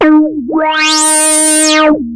OSCAR  9 D#4.wav